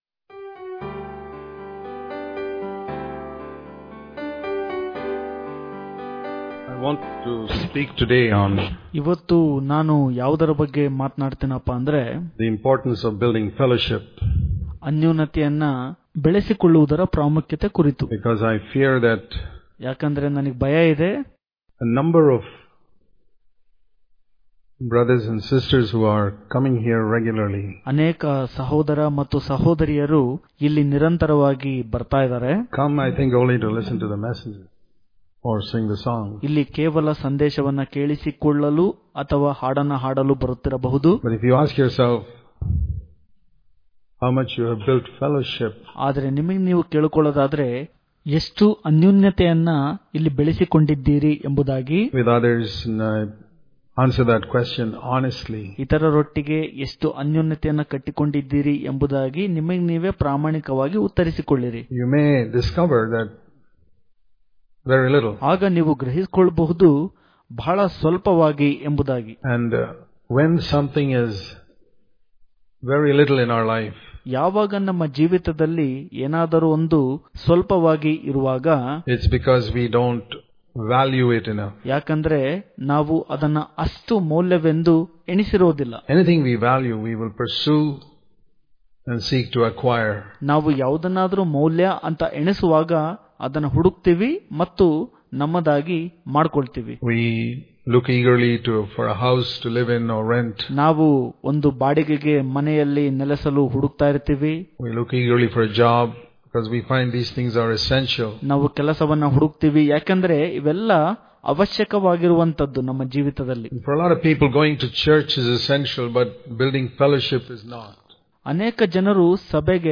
March 31 | Kannada Daily Devotion | You Can Only Grow In The Fellowship When You Stop Acting Daily Devotions